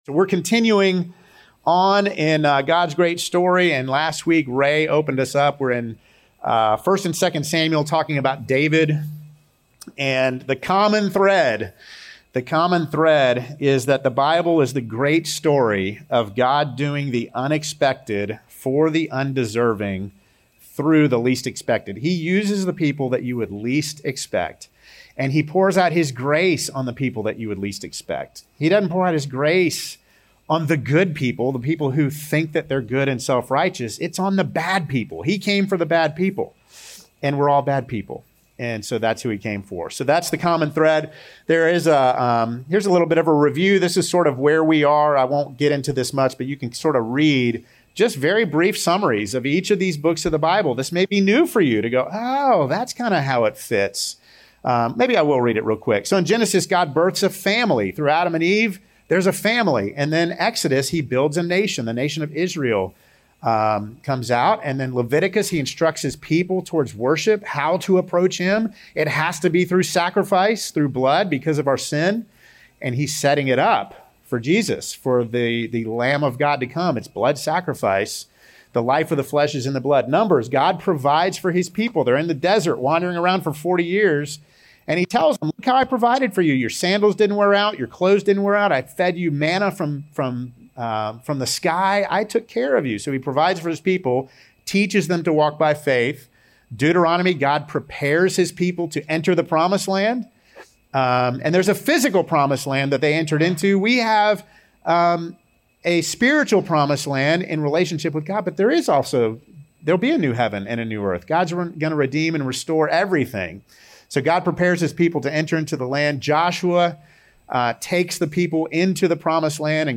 Sermons | Good News Church Georgia